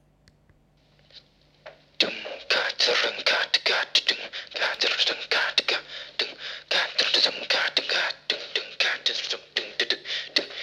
recorded (I can’t even call it beatboxing–it’s more like I just coughed into the recorder…) while watching over the movers. At the airport I picked that idea to start a new track, and this is what I came up with while waiting for our flight.
beatidea.mp3